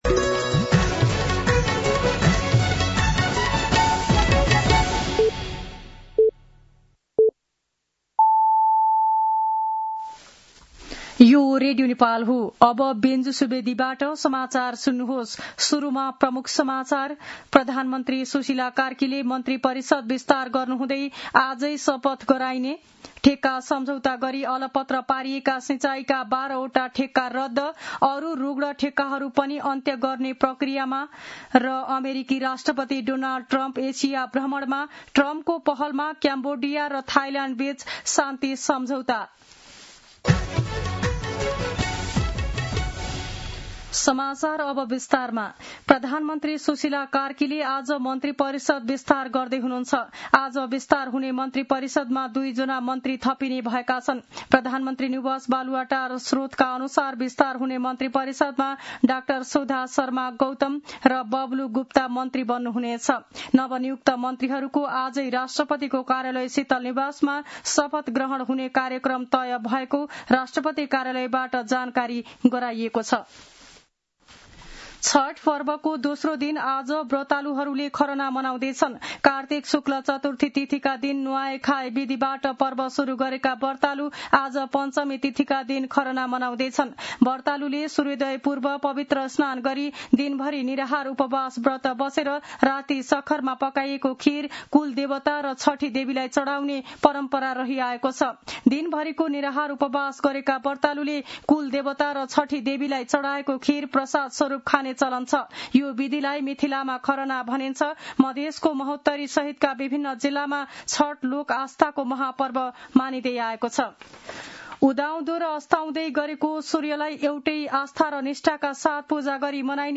दिउँसो ३ बजेको नेपाली समाचार : ९ कार्तिक , २०८२
3-pm-News-1.mp3